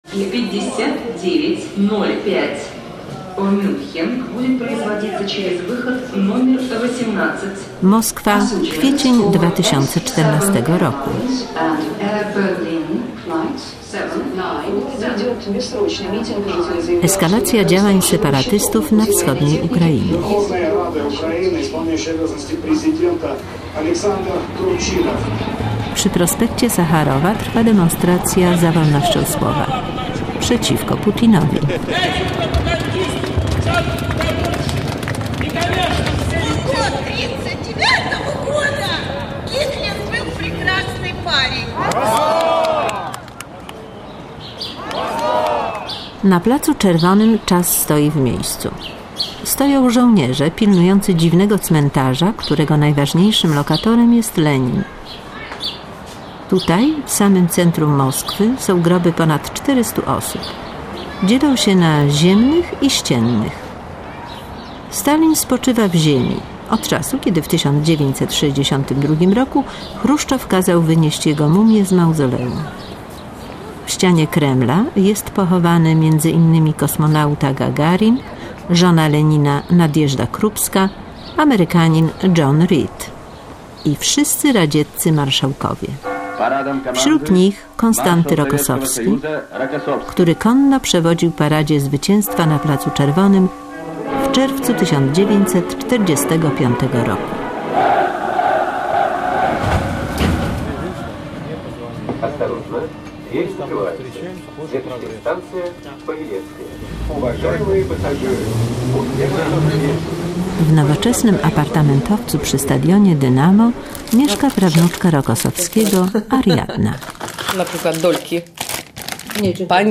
audycja nominowana do prestiżowej nagrody Prix Europa 2014 w kategorii Dokument